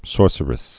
(sôrsər-ĭs)